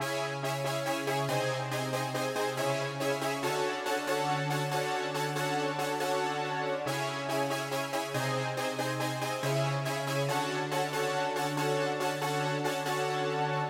铜管乐器高八度 Bpm 140
Tag: 140 bpm Trap Loops Brass Loops 2.31 MB wav Key : Unknown